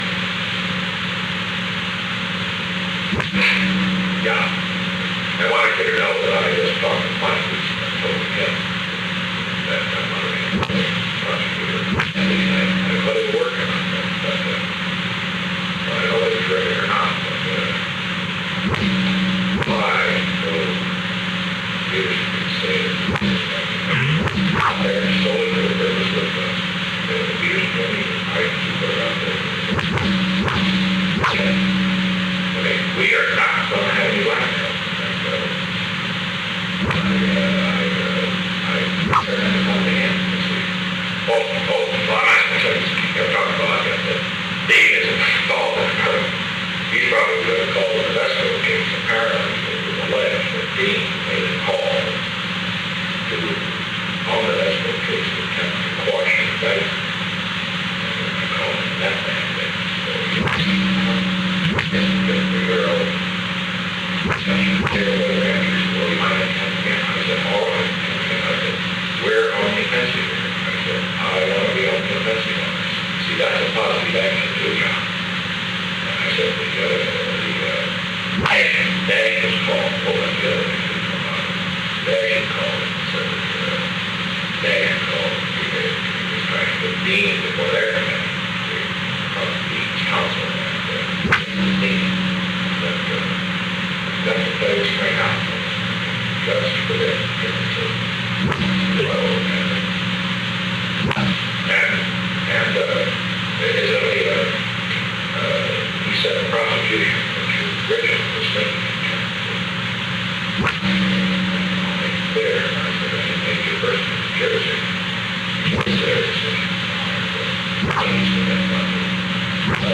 Secret White House Tapes
Location: Executive Office Building
The President talked with John D. Ehrlichman.